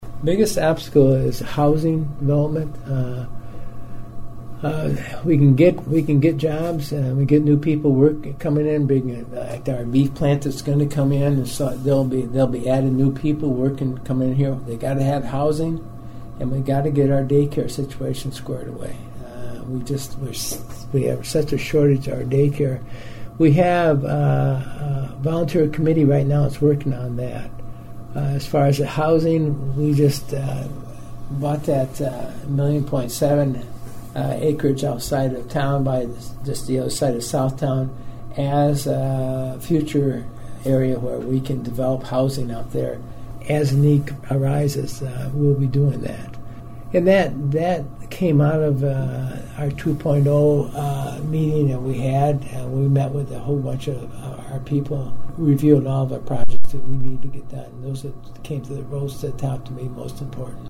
I spoke with Mayor Harrington about the future of the city of Huron including its obstacles to improvement.